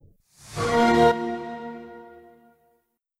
XBOX One Beta Startup.wav